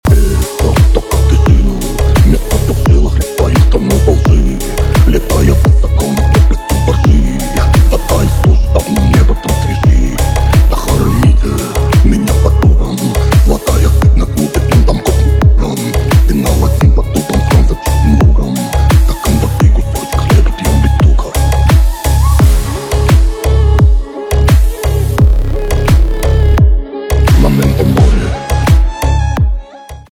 русский рэп
битовые , басы , необычные , качающие , жесткие